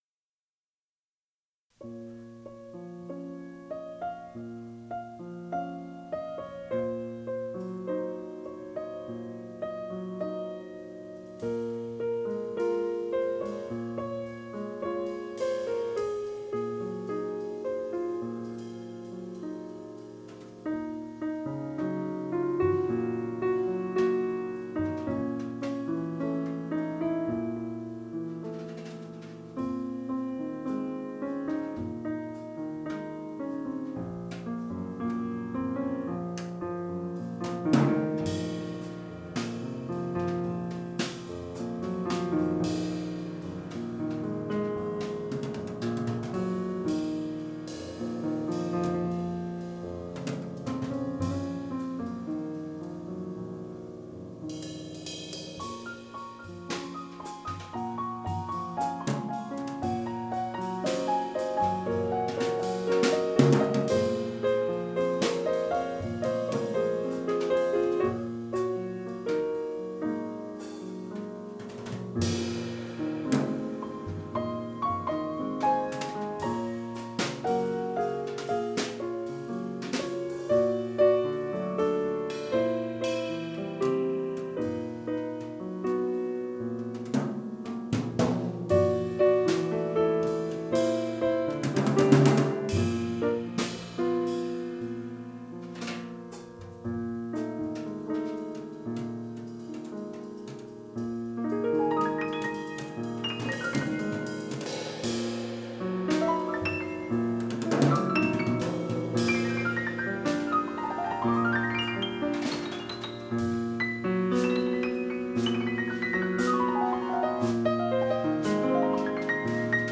piano
batterie